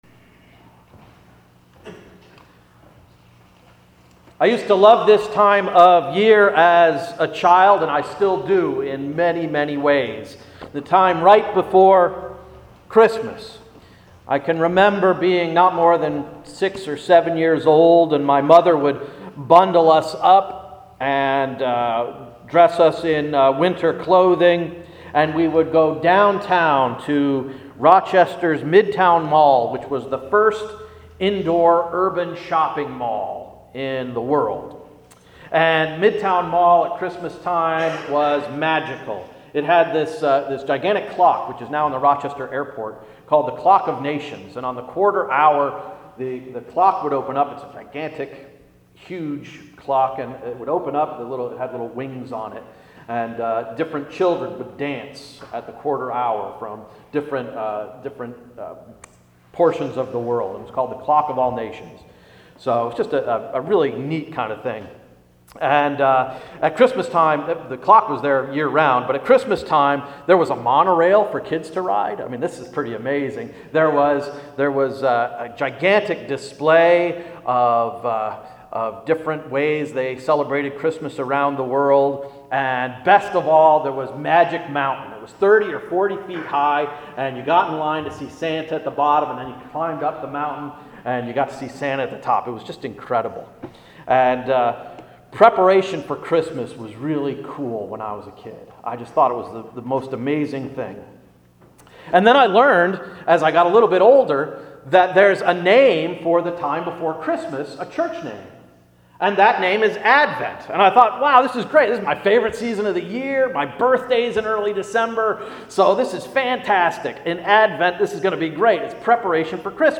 Sermon of December 13th–“Zepha-who? In Advent?”